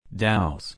/daʊs/